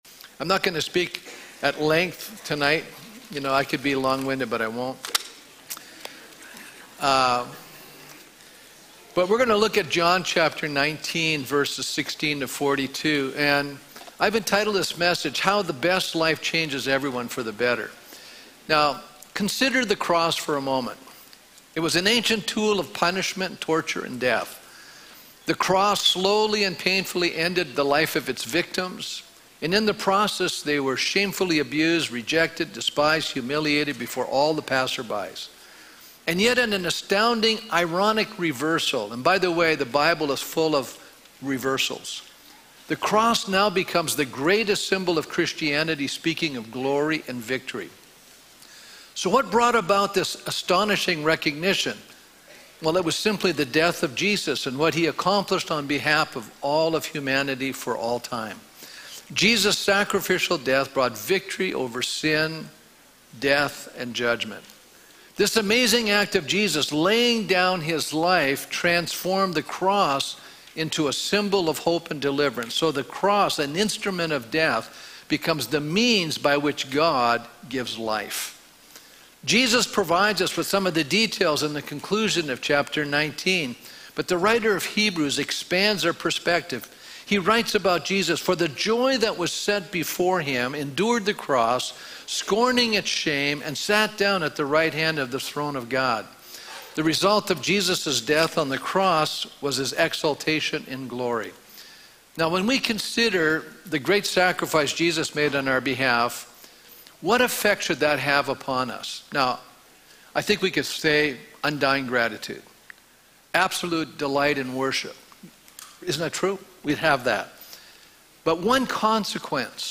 Good Friday Service Consider the cross, an ancient tool of punishment, torture, and death.